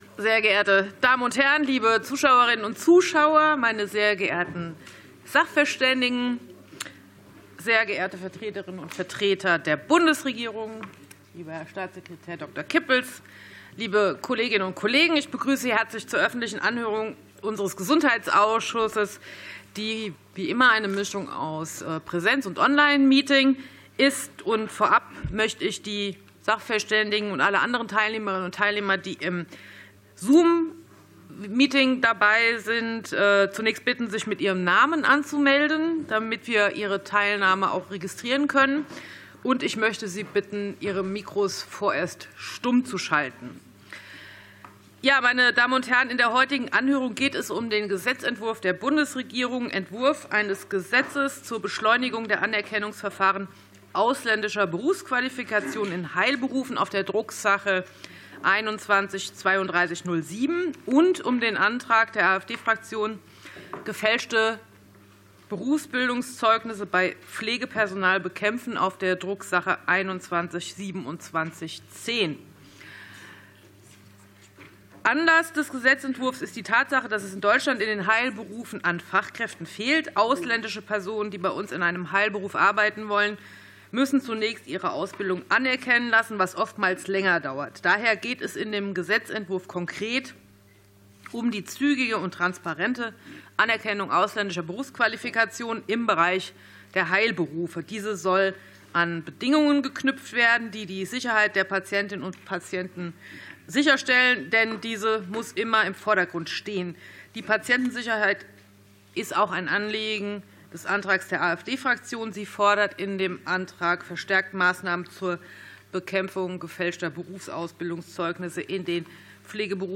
Anhörung des Ausschusses für Gesundheit